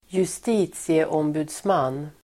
Uttal: [²just'i:tsieåmbu:dsman:]